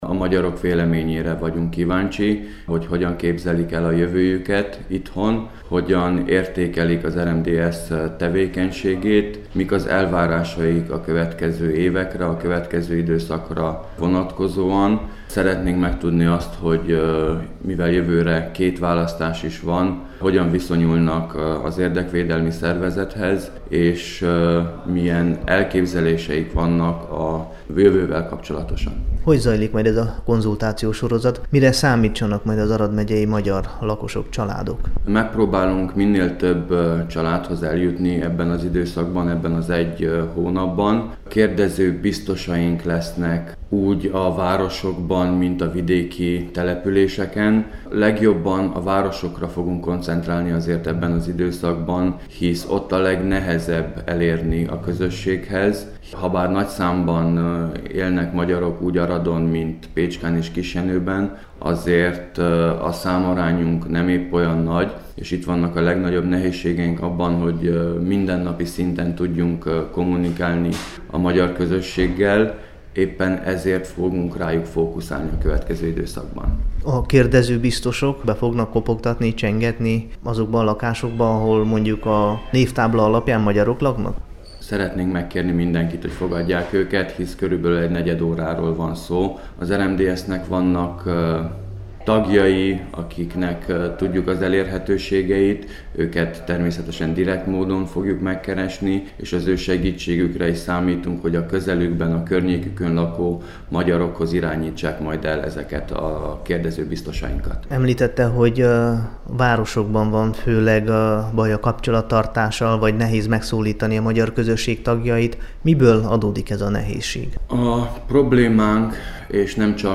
a Kossuth Rádió Határok nélkül című műsorában.